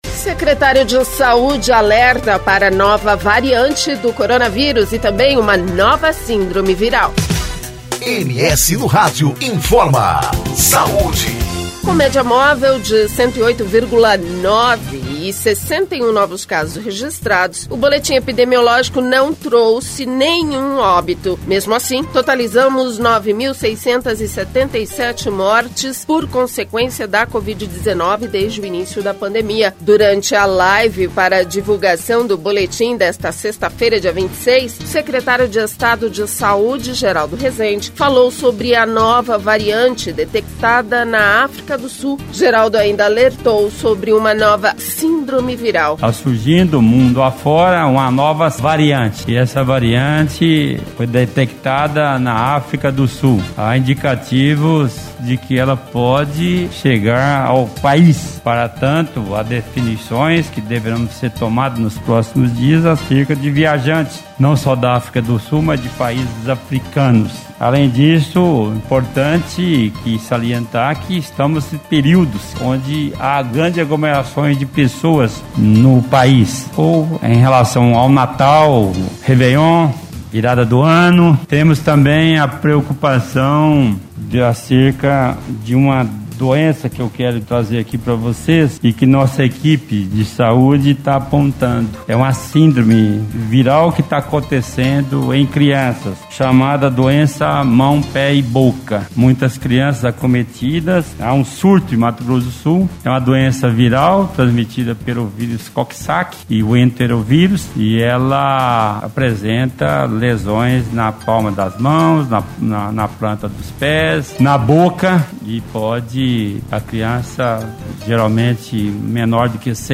Durante a live para divulgação do boletim, o secretário de Estado de Saúde, Geraldo Resende, falou sobre a nova variante detectada na África do Sul. Geraldo ainda alertou sobre uma nova síndrome viral.